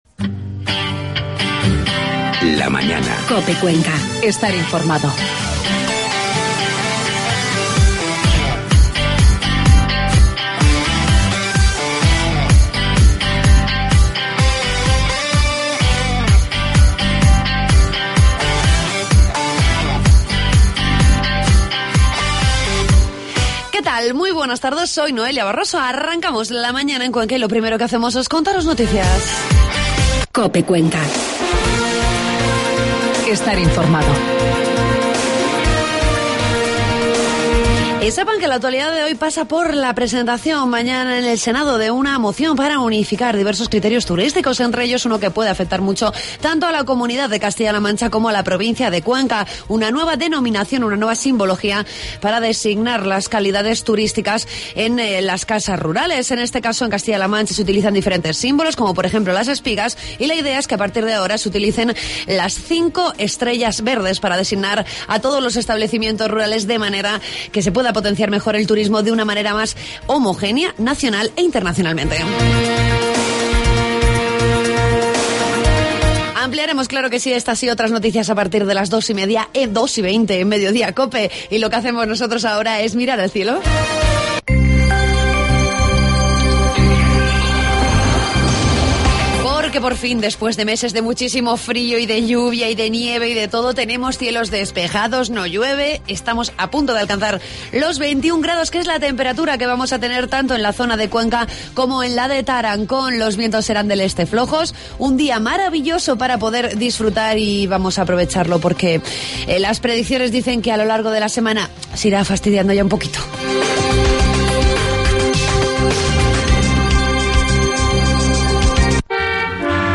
Entrevistamos al diputado de Fomento, Javier Parrilla, con el que hablamos sobre la adjudicación del Camino de Casalonga.